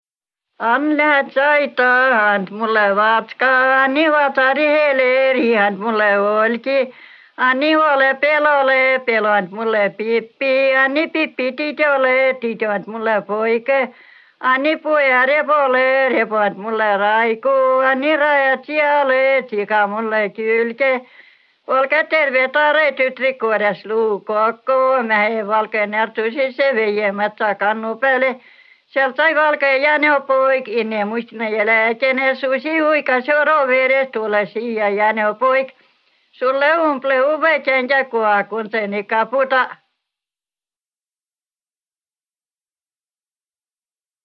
Laul “Ann läts aita”